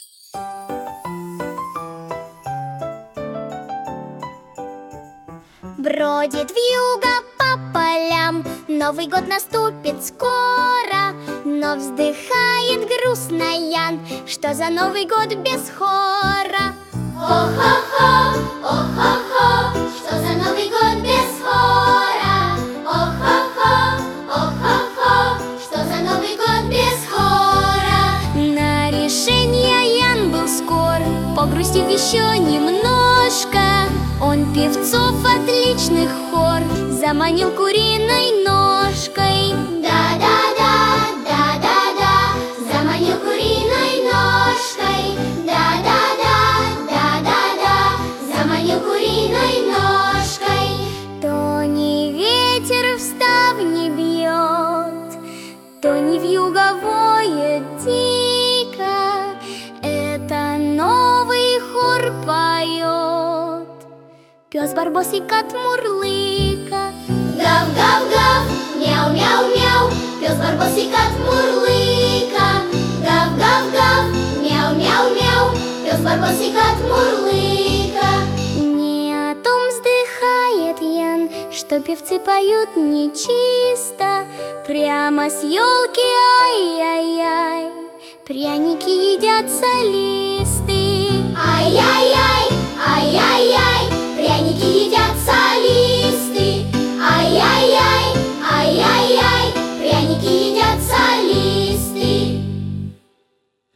• Категория: Детские песни
Эстонская народная песня